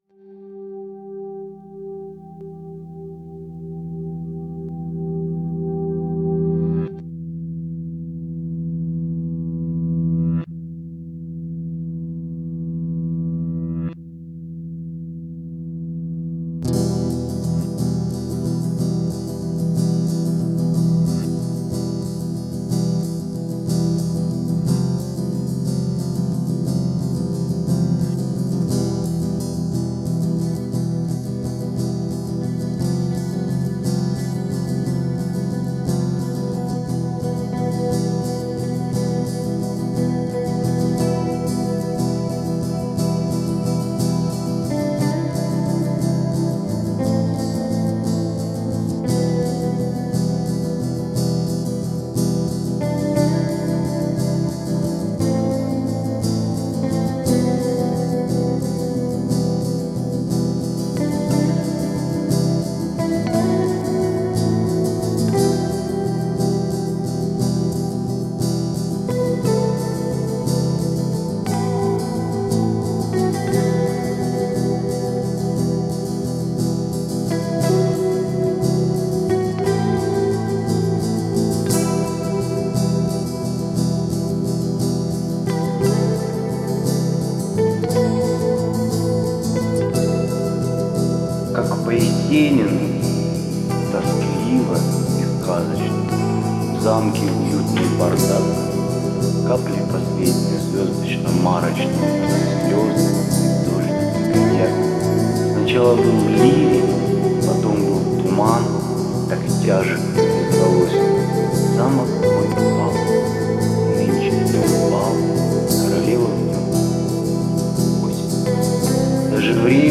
декламация